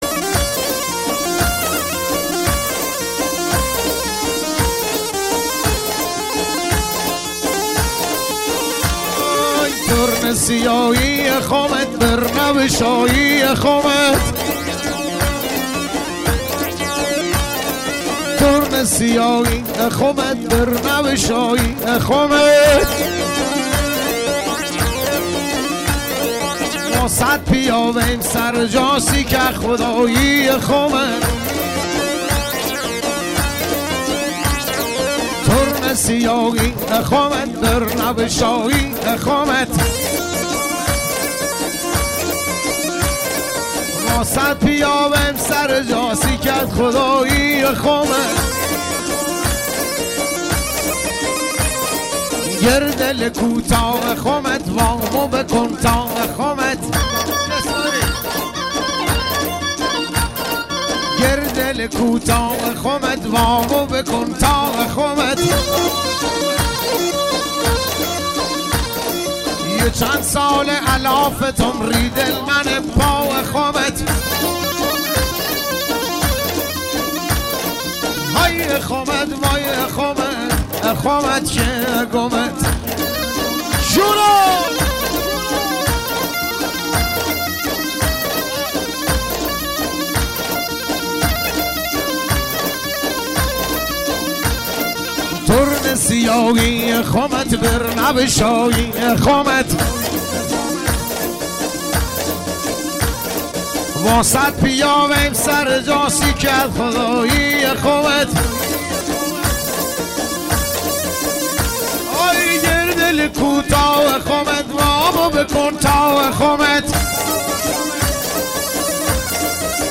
محلی لری